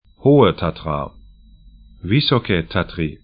Tatra, Hohe 'ho:ə 'tatra Vysoké Tatry 'vi:sɔkɛ: 'tatri sk Gebirge / mountains 49°10'N, 20°08'E